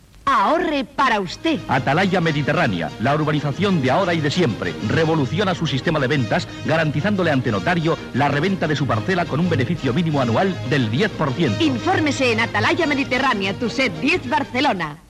Publicitat d'Atalaya Mediterránea Gènere radiofònic Publicitat